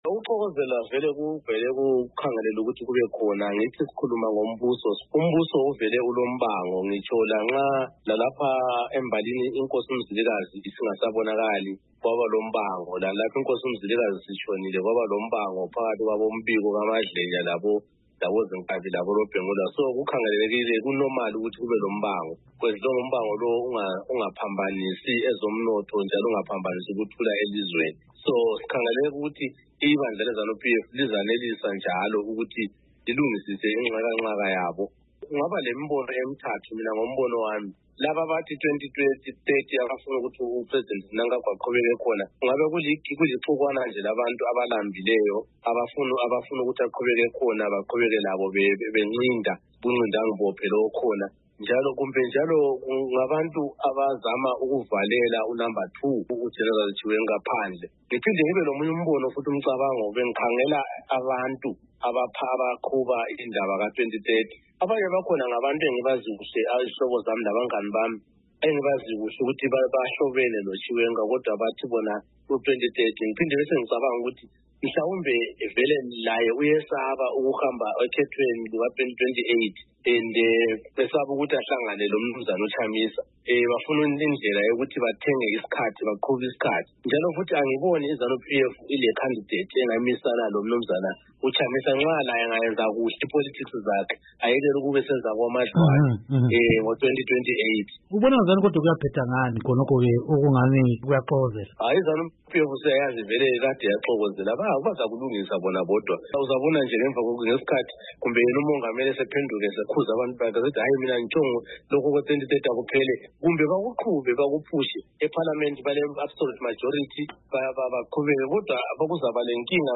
Ingxoxo